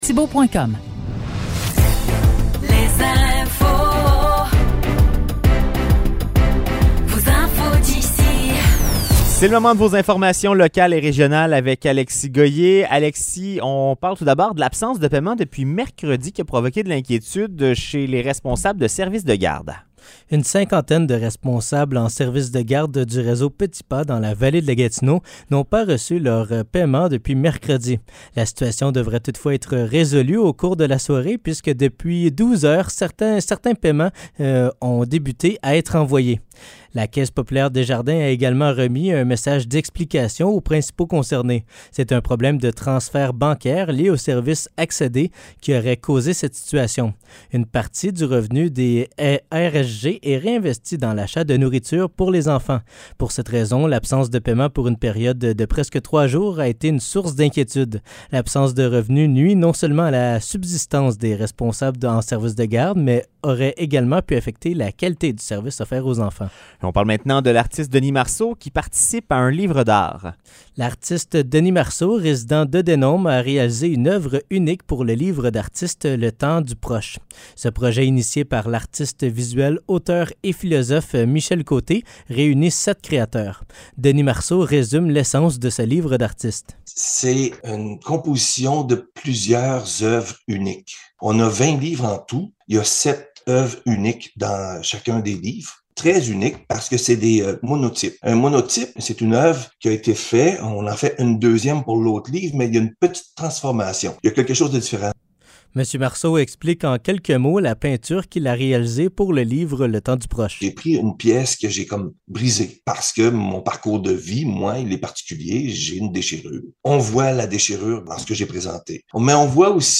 Nouvelles locales - 13 octobre 2023 - 16 h